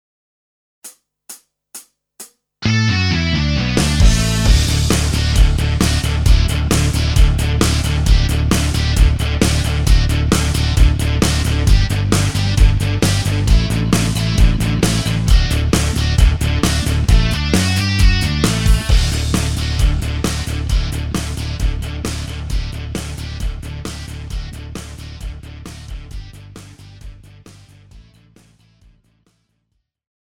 KARAOKE/FORMÁT:
Žánr: Rock
BPM: 132
Key: D